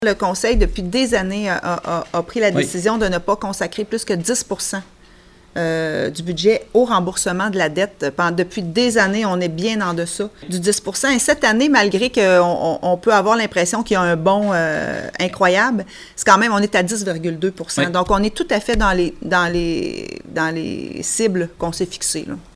La mairesse, Geneviève Dubois, signale toutefois que la dette respecte le plafond que s’est imposé le conseil municipal.